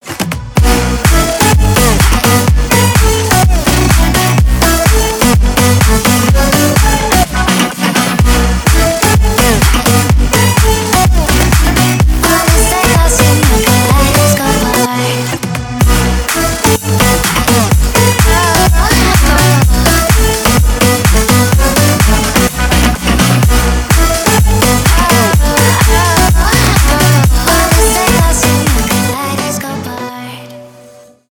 Электроника
клубные # громкие